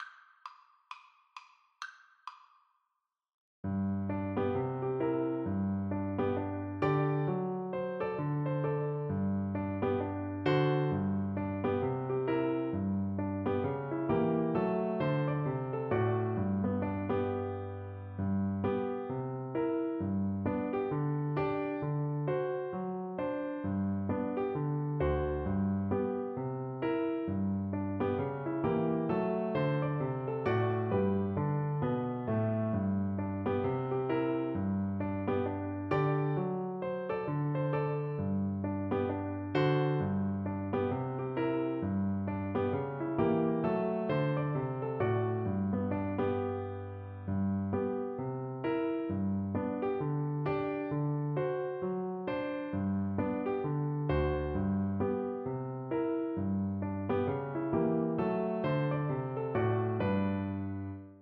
Cello
Traditional Music of unknown author.
4/4 (View more 4/4 Music)
G major (Sounding Pitch) (View more G major Music for Cello )
Swing = 132